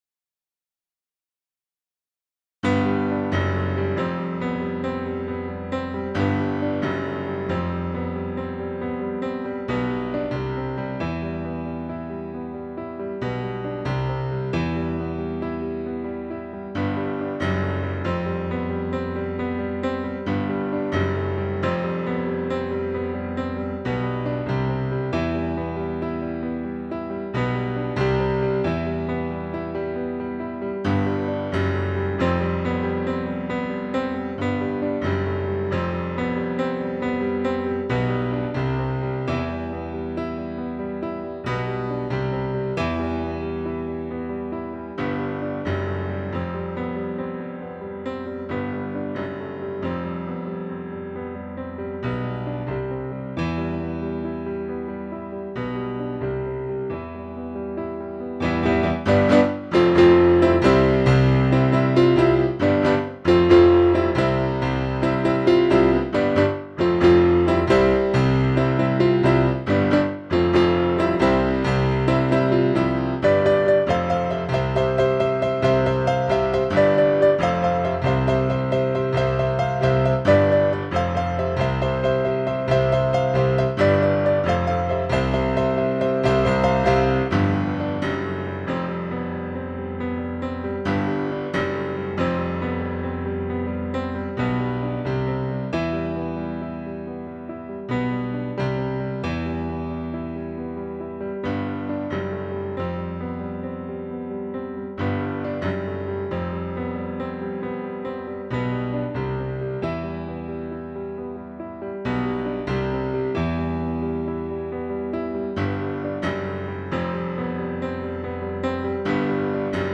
Stienway Piano.wav